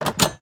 Minecraft Version Minecraft Version snapshot Latest Release | Latest Snapshot snapshot / assets / minecraft / sounds / block / iron_door / open2.ogg Compare With Compare With Latest Release | Latest Snapshot